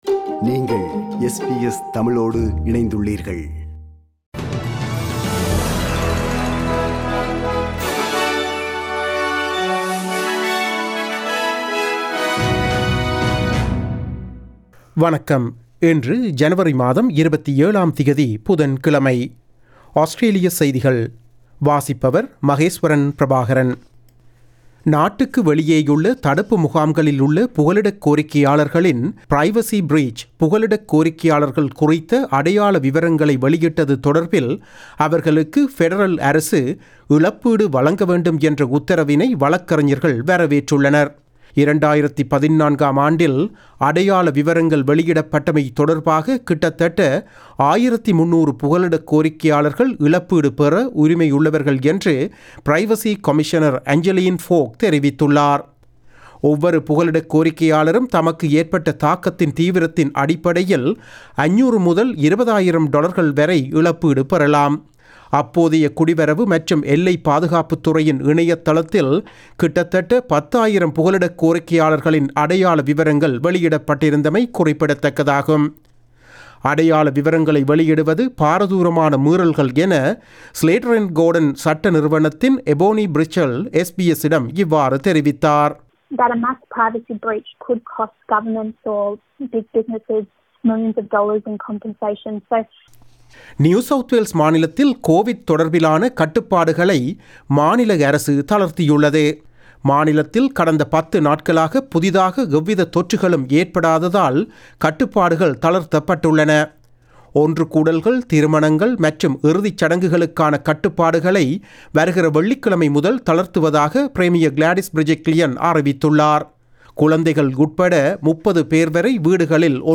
Australian news bulletin for Wednesday 27 January 2021.